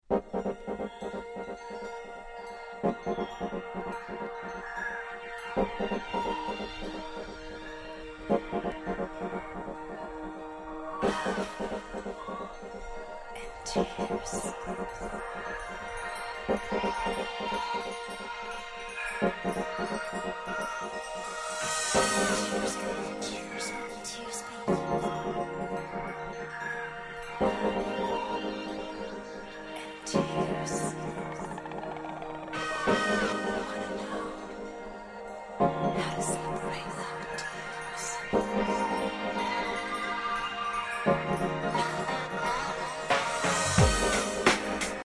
浮遊感のあるトラックが堪りません。